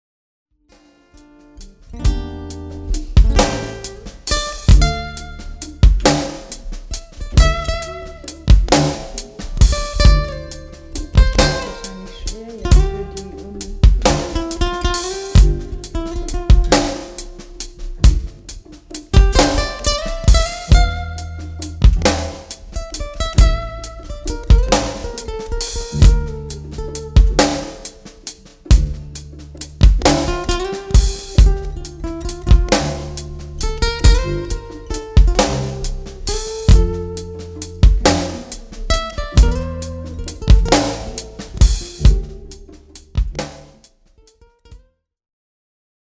Als Tonabnehmer ist ein B-Band UST eingebaut, der gefällt mir schon an meiner Stahlsaitengitarre sehr gut, das war mir also sehr recht und als Anschlüsse gibt es Klinke und XLR.
Es ging nur um eine Pilotspur, also bin ich einfach - um im Regieraum bleiben zu können und die Aufnahme zu steuern - aus dem XLR-Ausgang der Ibanez direkt in das Recordingsystem gegangen.
Ibanez EWN30 direct - mp3
Das ist jetzt ohne weitere Nachbearbeitung am EQ, einfach nur die Gitarre mit dem eingebauten Tonabnehmer. Mir persönlich ist es noch ein klein bisschen zu spitz, dafür muss ich aber nur den Höhenregler an der Gitarre wieder ein klein wenig zurück nehmen.